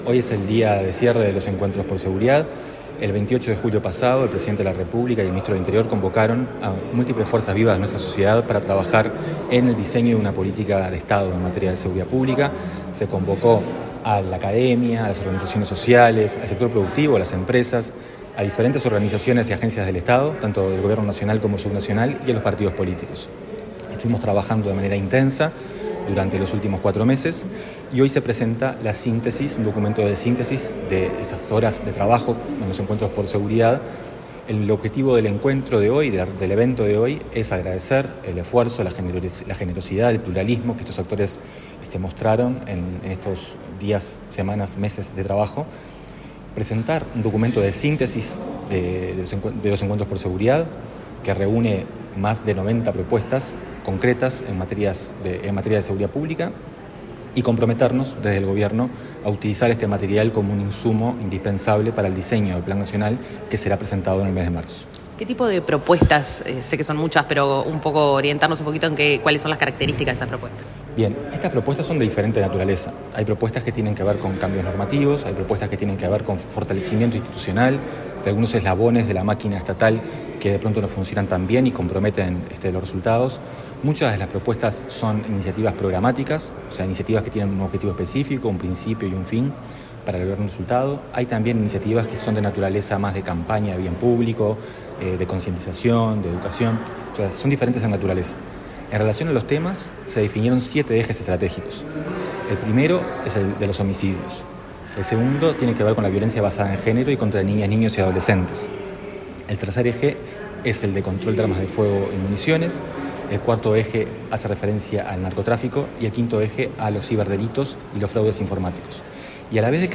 Declaraciones del coordinador del Plan Nacional de Seguridad Pública